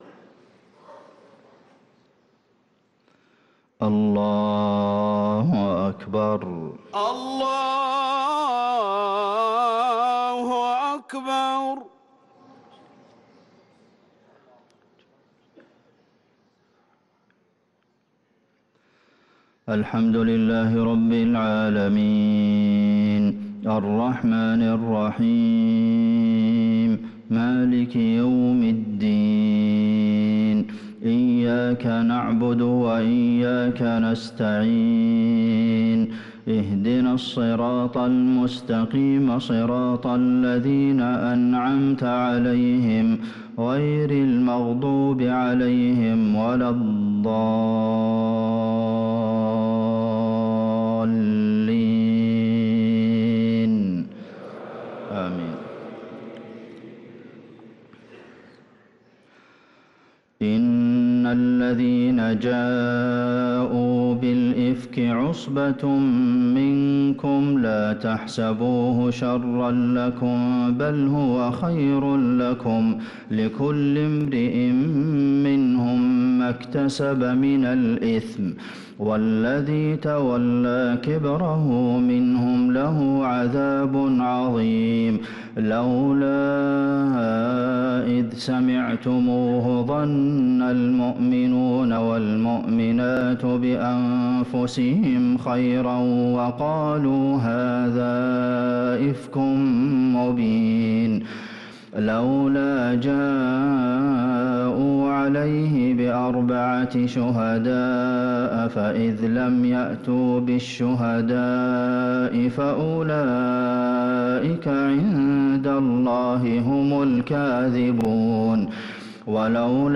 صلاة الفجر للقارئ عبدالمحسن القاسم 23 جمادي الأول 1445 هـ
تِلَاوَات الْحَرَمَيْن .